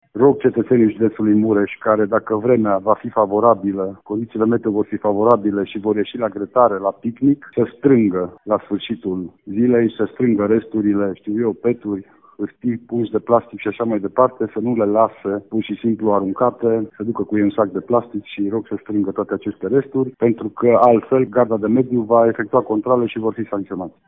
Prefectul Lucian Goga a făcut şi un apel la mureşenii care aleg să îşi petreacă 1 mai la iarbă verde – să fie atenţi cu mediul şi să nu lase în urmă deşeuri.